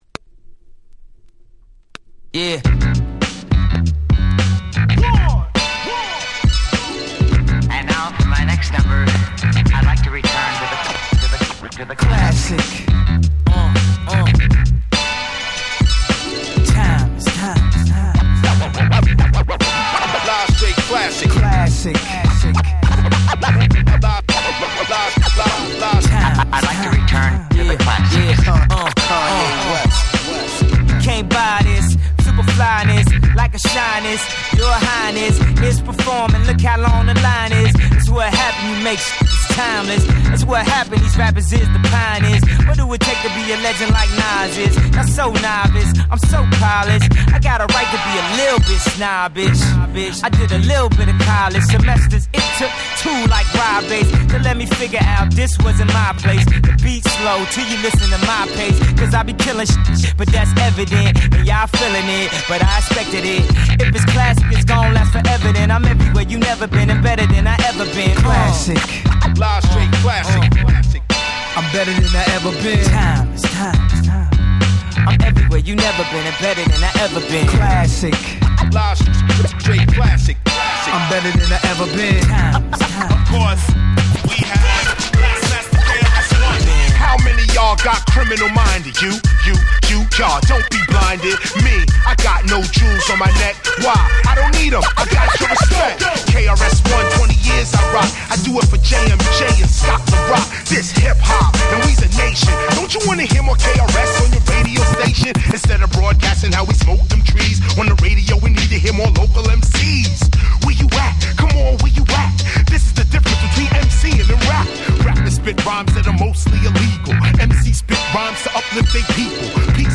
B-1頭に音に出る小傷あり。)
※各MCごとにBeatが変わる